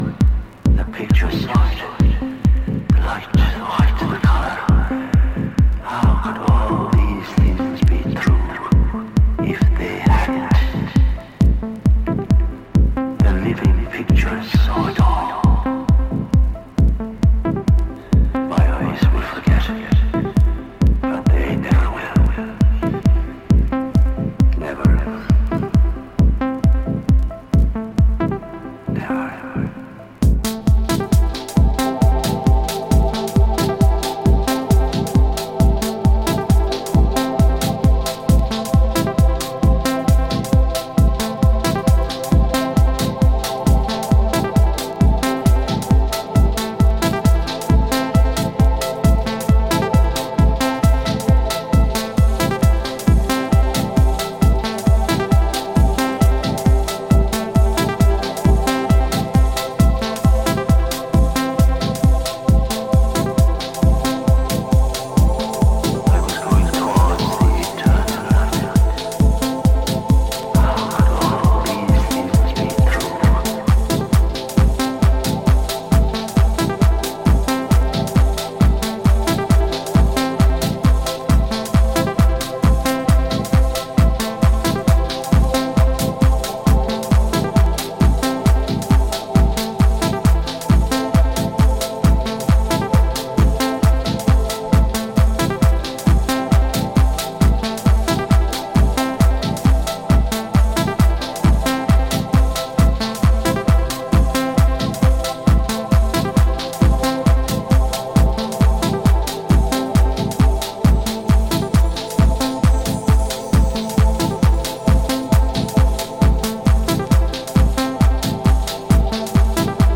Trance